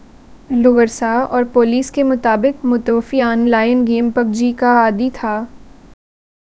Spoofed_TTS/Speaker_05/100.wav · CSALT/deepfake_detection_dataset_urdu at main
deepfake_detection_dataset_urdu / Spoofed_TTS /Speaker_05 /100.wav